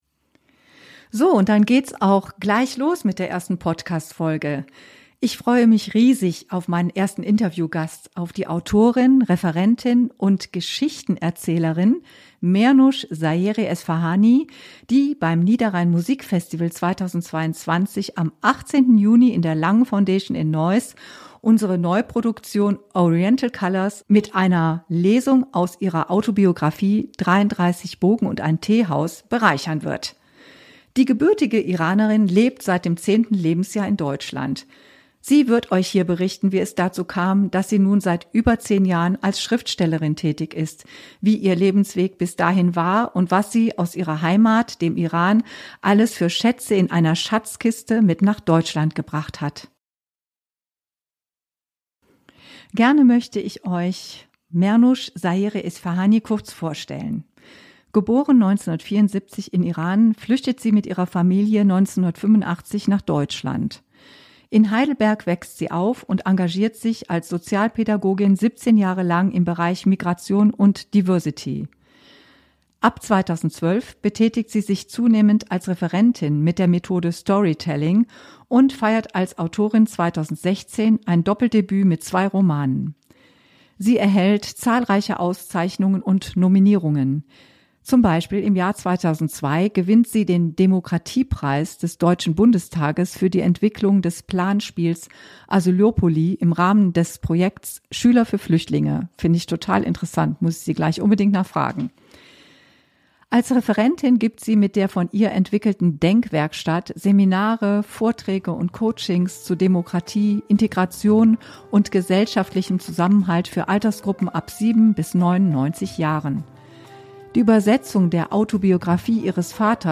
001 Wie man seine Heimat in ein neues Land mitbringt | Interview